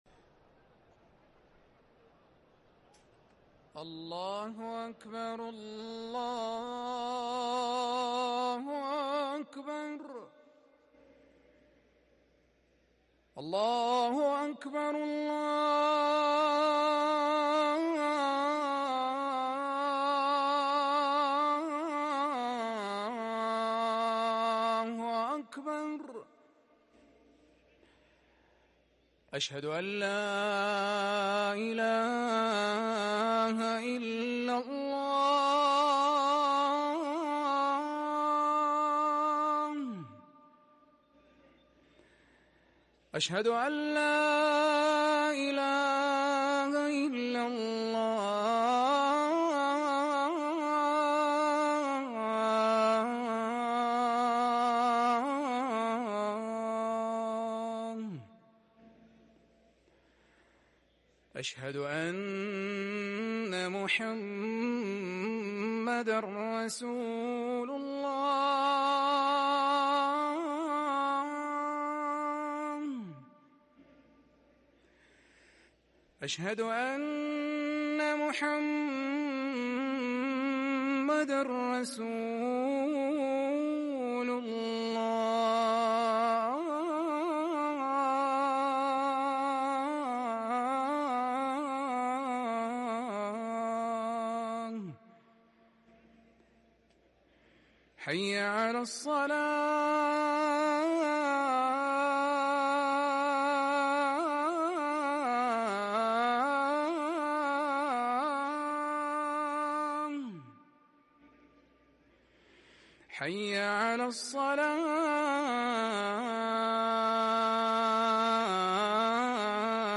اذان الظهر